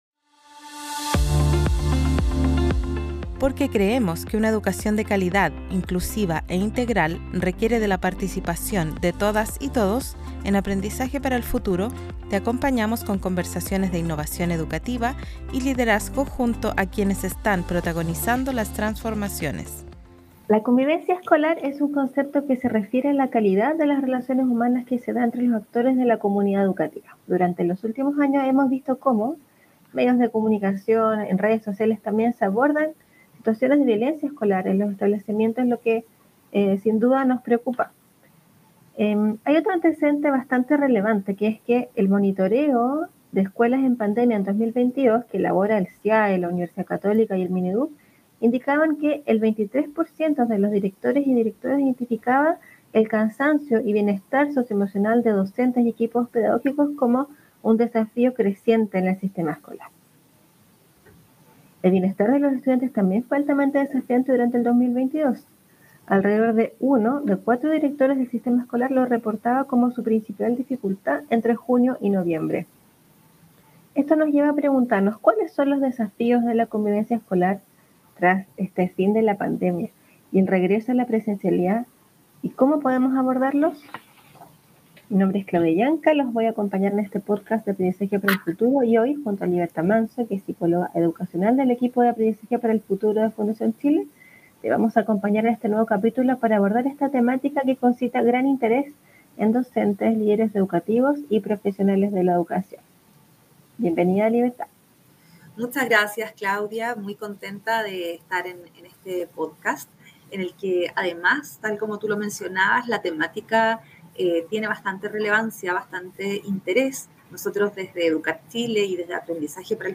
Este episodio se enfoca en la conversación sobre la convivencia escolar y la formación de equipos de convivencia en los establecimientos para mejorar el clima escolar y por ende los aprendizajes.